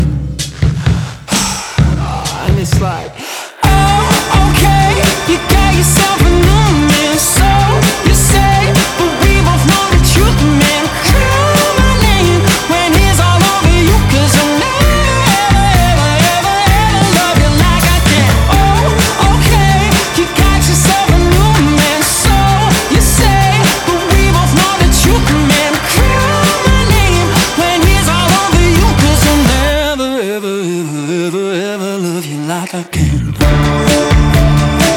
2025-05-23 Жанр: Поп музыка Длительность